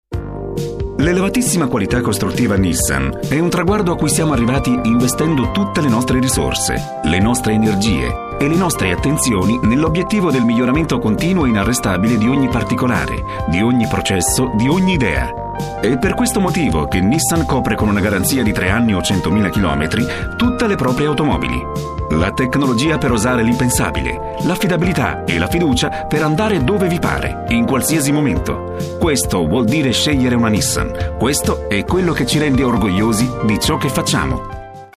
Radiocomunicati